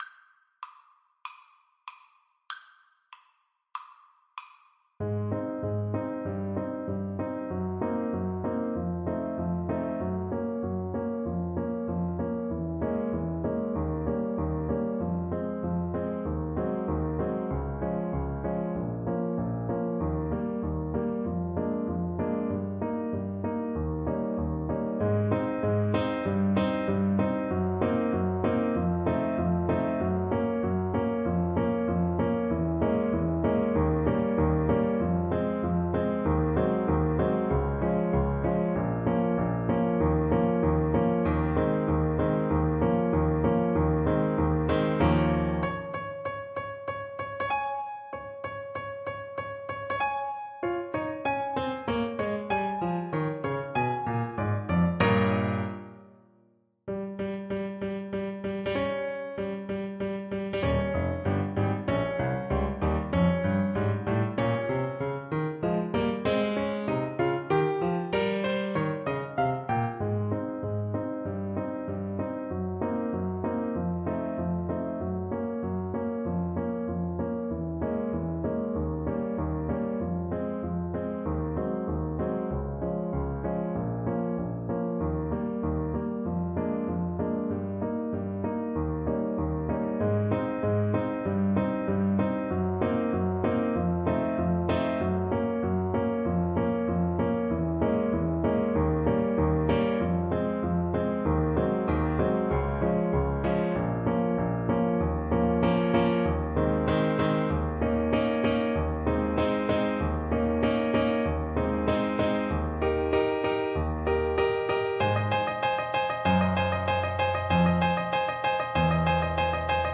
Play (or use space bar on your keyboard) Pause Music Playalong - Piano Accompaniment Playalong Band Accompaniment not yet available transpose reset tempo print settings full screen
Trumpet
Ab major (Sounding Pitch) Bb major (Trumpet in Bb) (View more Ab major Music for Trumpet )
Molto Allegro = c. 160 (View more music marked Allegro)
4/4 (View more 4/4 Music)
D5-F6
Classical (View more Classical Trumpet Music)